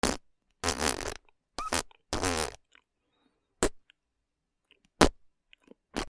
くち
/ G｜音を出すもの / G-50 その他 手をたたく　体